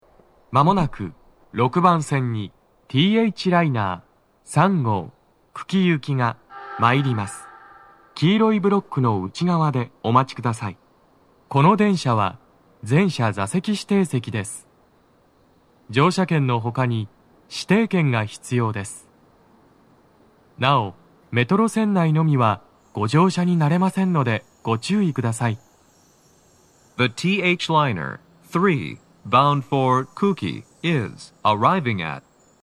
男声
接近放送7
放送は「THライナー 3号 久喜行」です。惜しくも最後の最後で切られています。